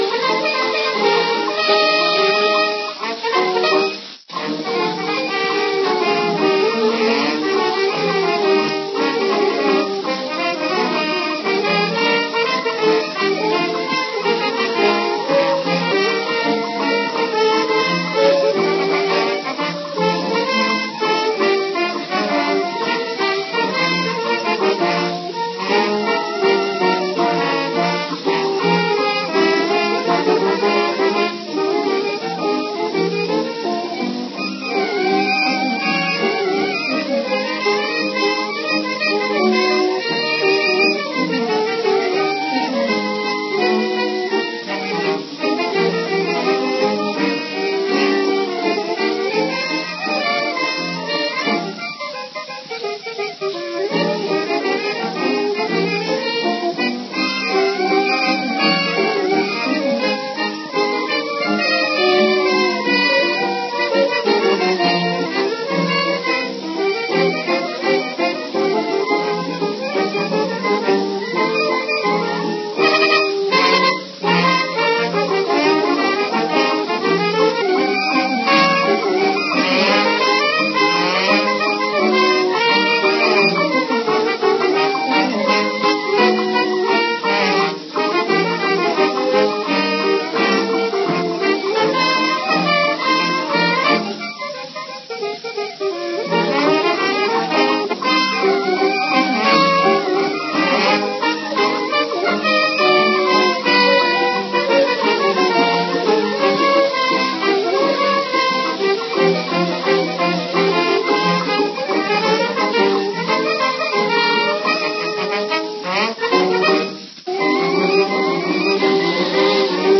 were an all African-American 70 piece musical unit